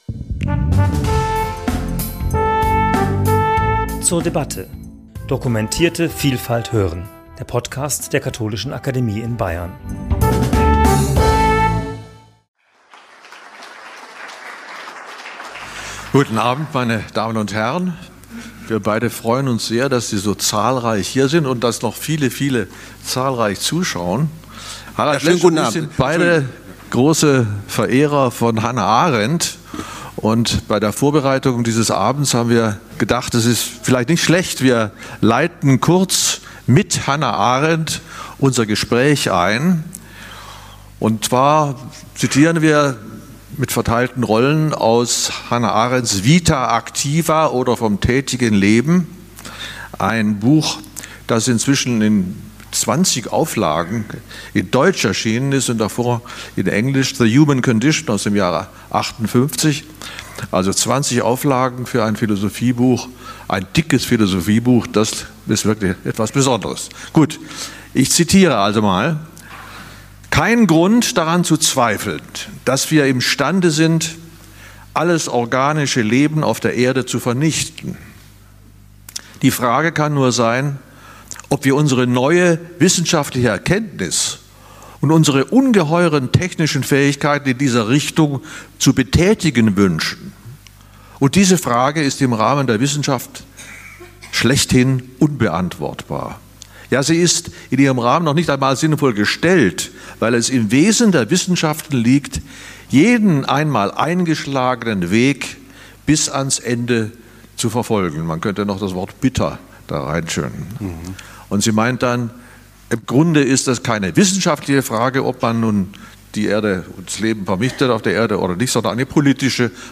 Podiumsdiskussion mit Harald Lesch und Wilhelm Vossenkuhl zum Thema 'Geist - Natur - Künstliche Intelligenz' ~ zur debatte Podcast
Mit einem Feuerwerk von Fragen wurden Harald Lesch und Wilhelm Vossenkuhl nach ihrer Diskussion zum Thema KI konfrontiert.